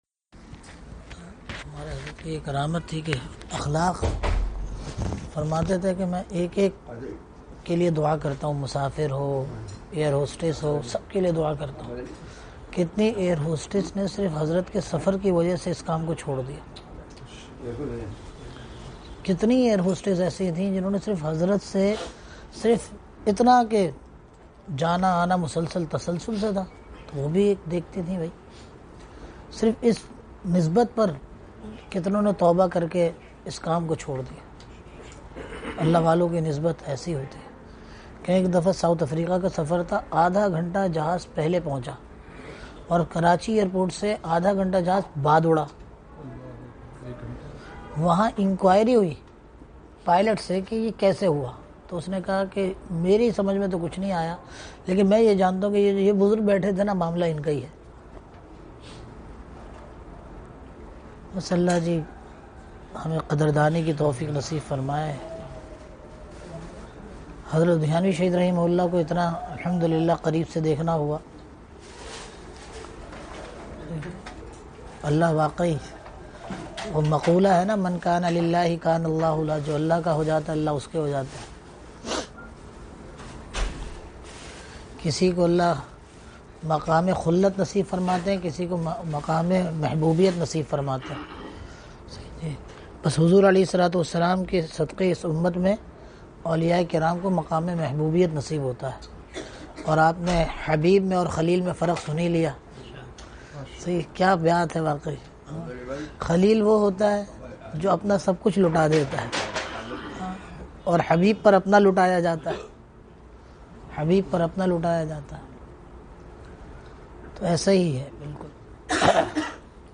Harmeen shareefen ki hazri or shokar guzari (bad namaz e isha madeena monawwra hotal)
Bayanat